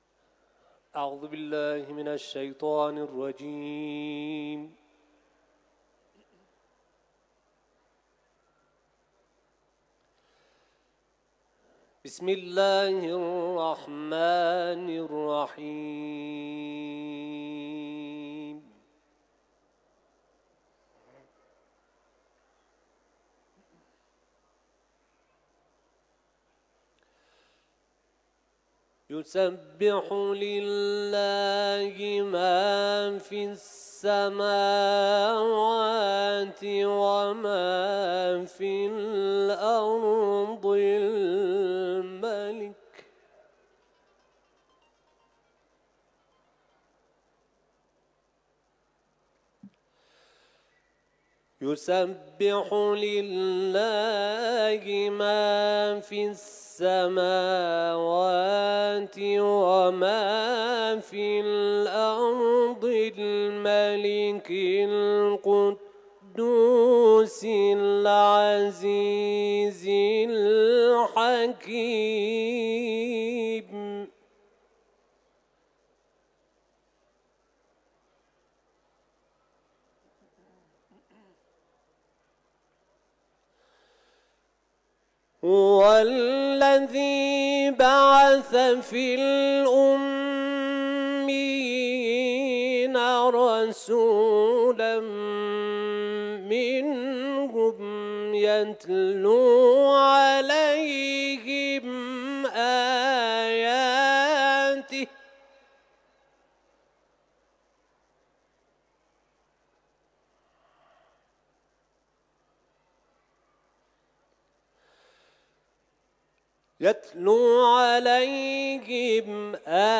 تلاوت
آیات ابتدایی سوره «جمعه» را در حرم مطهر رضوی، تلاوت کرده است